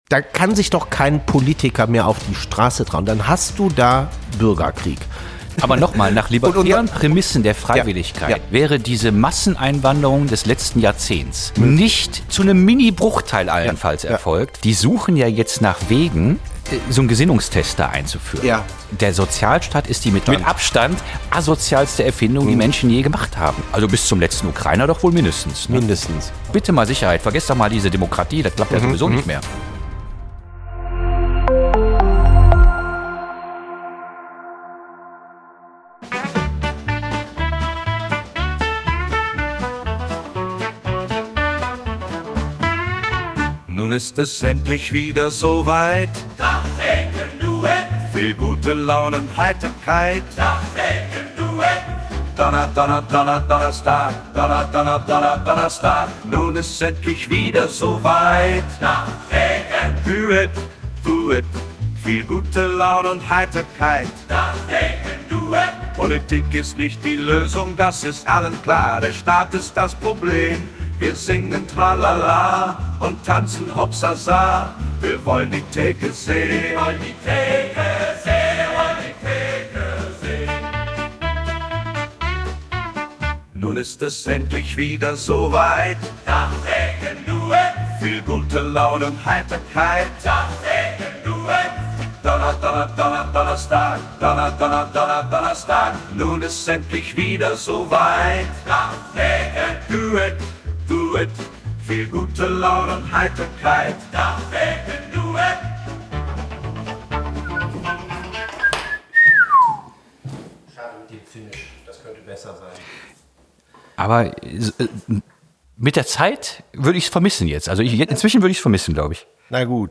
Kleine Redaktionskonferenz am Tresen